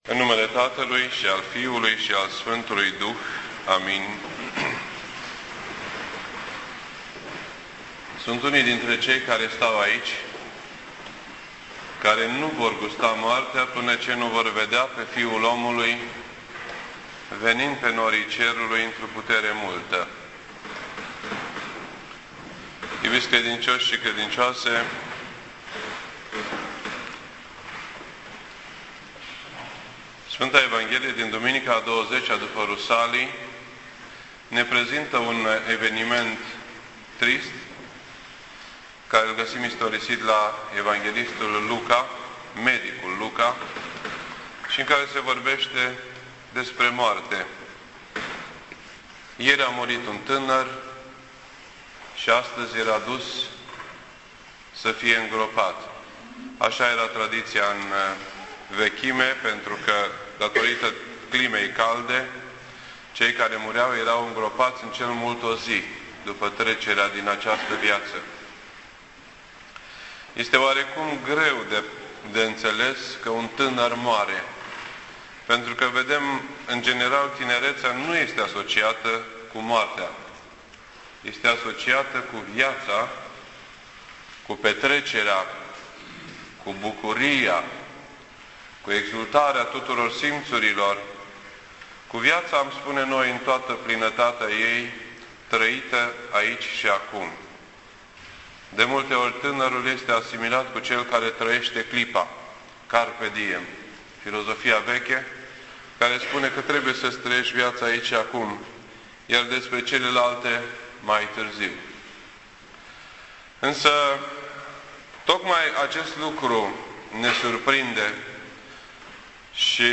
This entry was posted on Sunday, October 10th, 2010 at 9:18 PM and is filed under Predici ortodoxe in format audio.